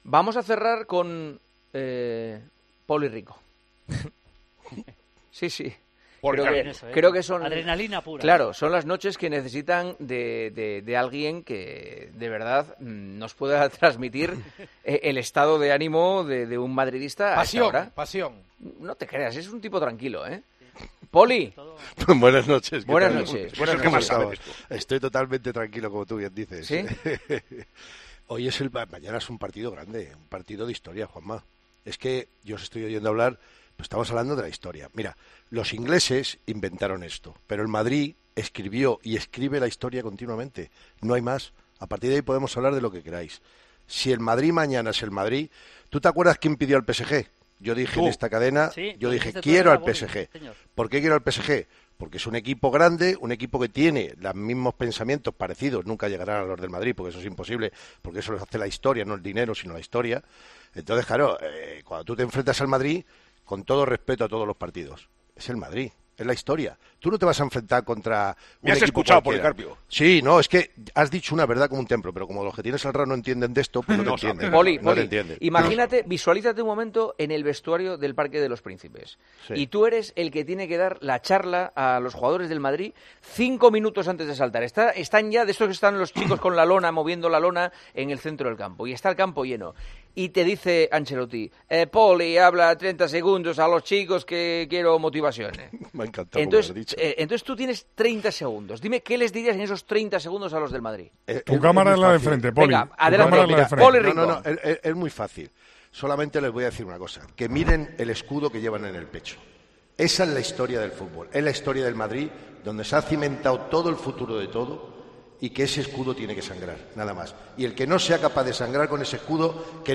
El comentarista de 'Tiempo de Juego' y de 'El Partidazo de COPE' contó a Juanma Castaño lo que le diría a los jugadores en los instantes previos a salir al Parque de los Príncipes.